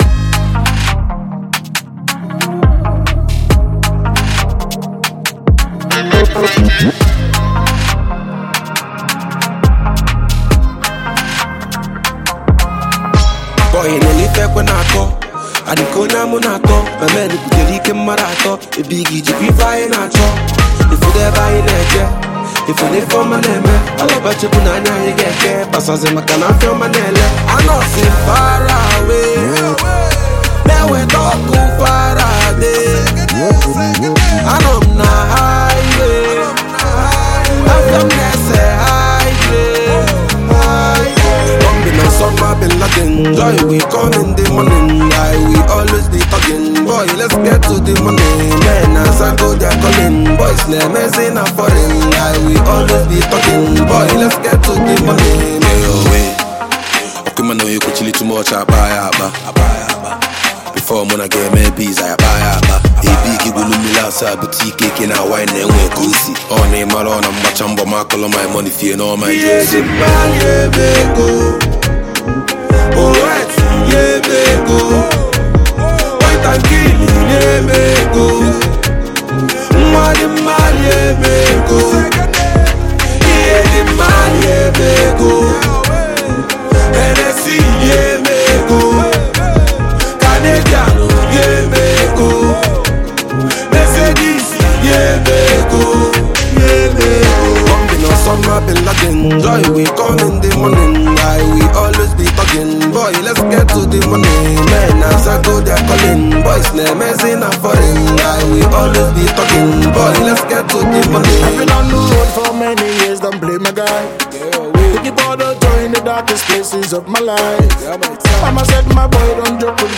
Afrobeats
With nice vocals and high instrumental equipments